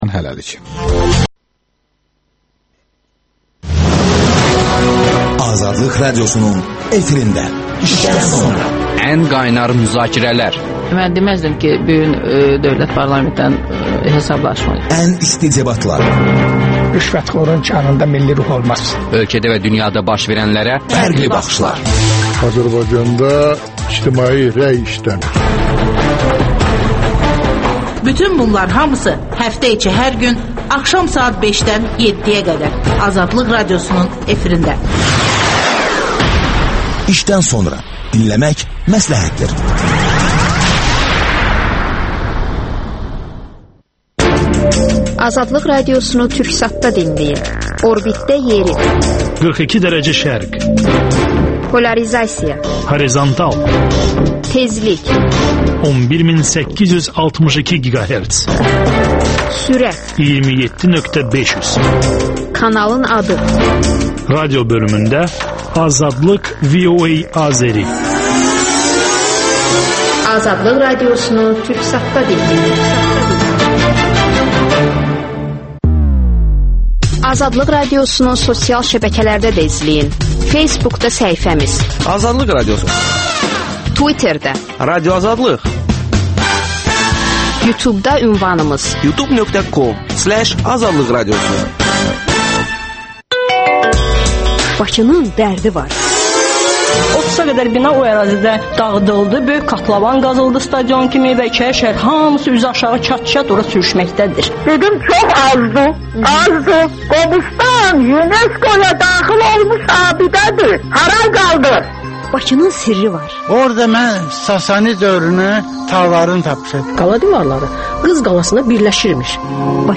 Deputat, Milli Məclisin İnsan haqları komitəsinin üzvü Aytən Mustafayeva canlı efirdə suallara cavab verir